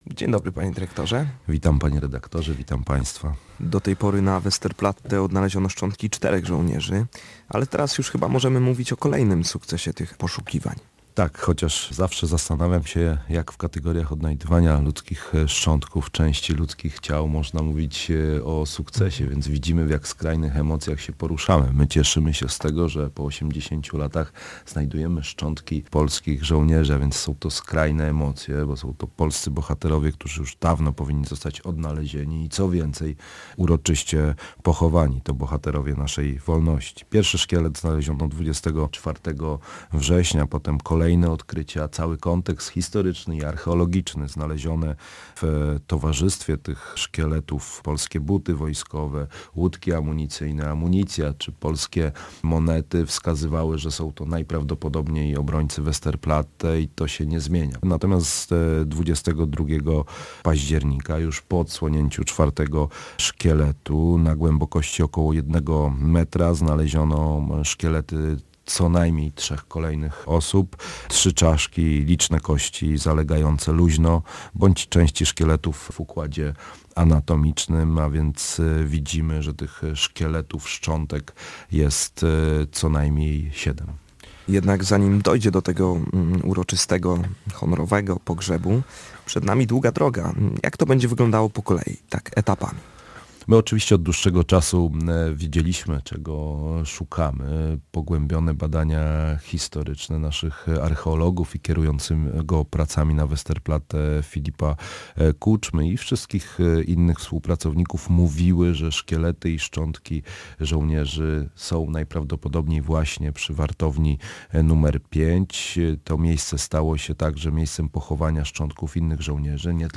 My od dłuższego czasu wiedzieliśmy czego szukamy, pogłębione badania naszych archeologów mówiły, że szczątki żołnierzy są najprawdopodobniej przy wartowni nr 5 – komentuje w rozmowie z nami dyrektor muzeum.